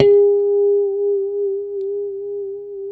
A4 PICKHRM2B.wav